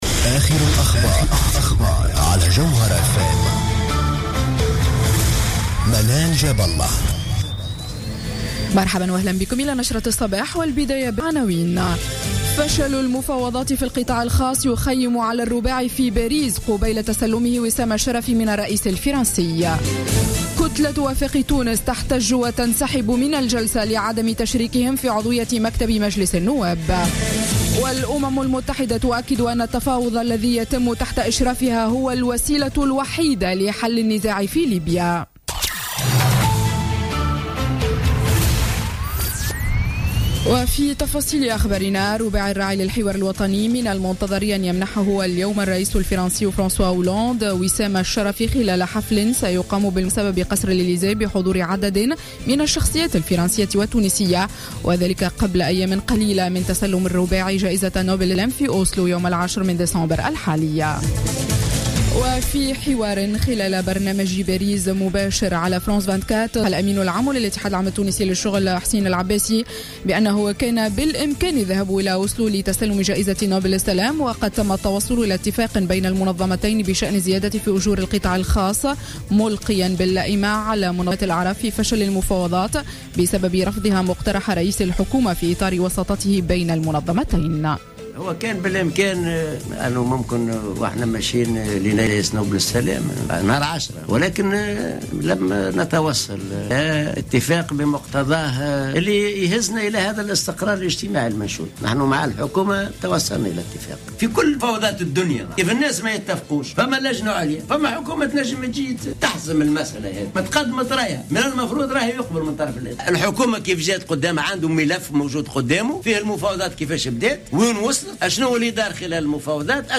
نشرة أخبار السابعة صباحا ليوم الثلاثاء 8 ديسمبر 2015